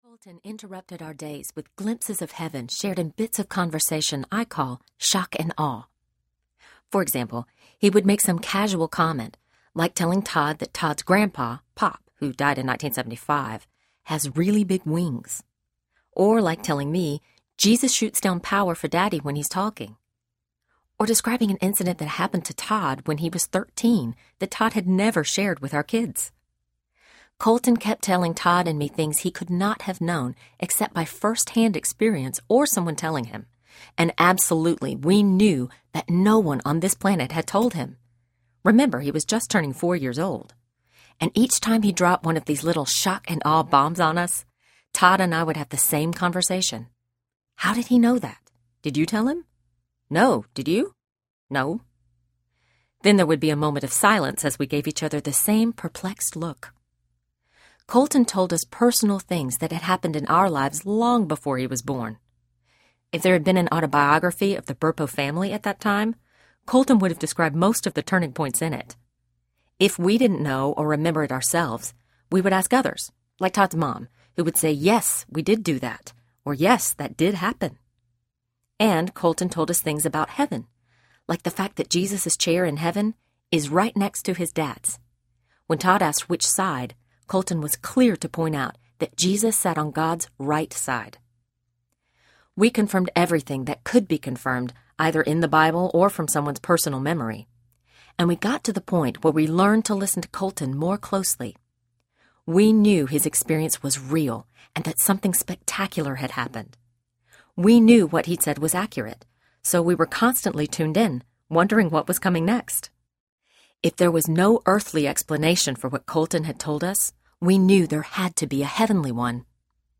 Heaven Changes Everything Audiobook
3.9 Hrs. – Unabridged